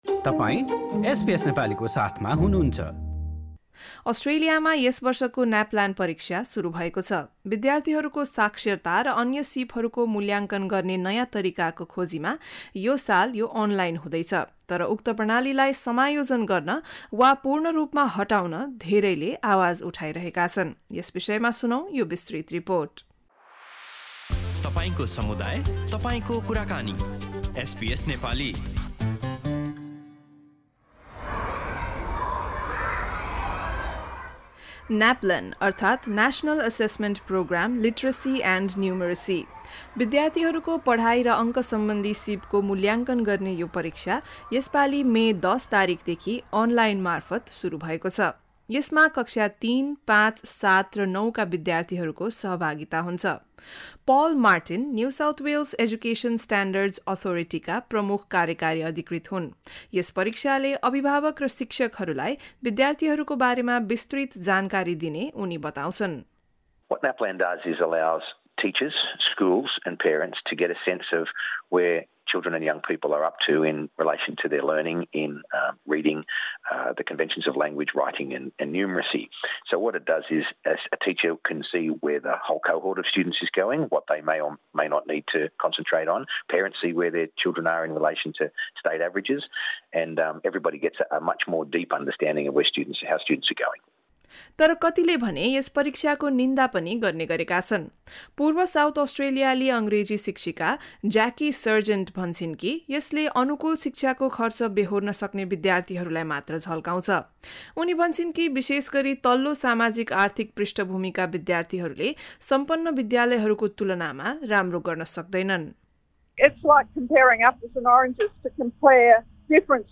यस रिपोर्टमा सुन्नुहोस्: न्यापल्यान परीक्षा बारे असन्तुष्टि किन?